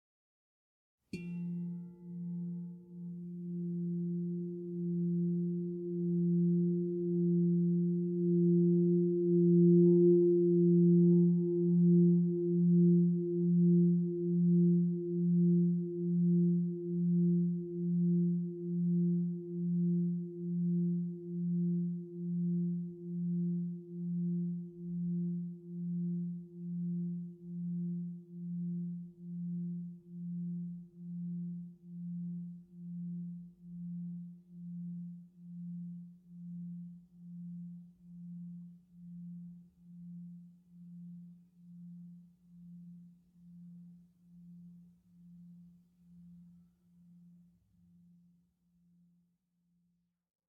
Meinl Sonic Energy 8" Essence Crystal Singing Bowl F3, Mint Blue, 440 Hz, Heart Chakra (ECSB8F3MB)
The Meinl Sonic Energy Essence Crystal Singing Bowls are made of high-purity quartz to create a pleasant aura with their sound and design.